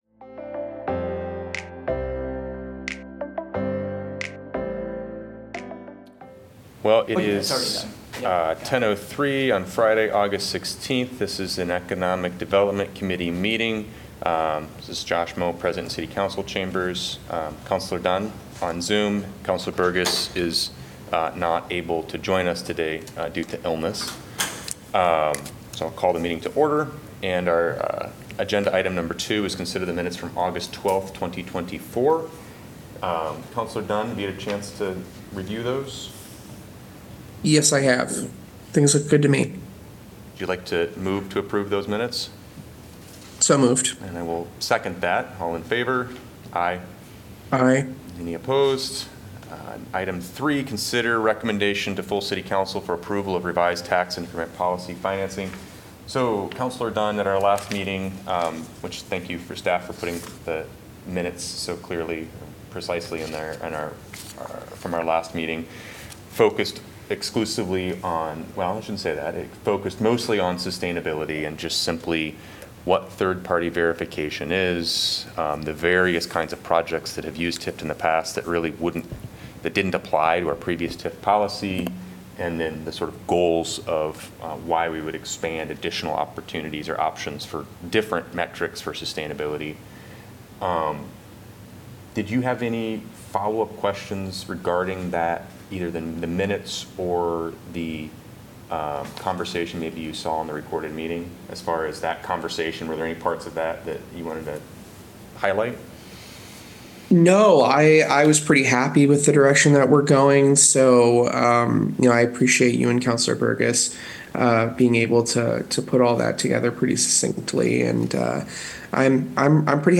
A meeting of the Iowa City City Council's Economic Development Committee.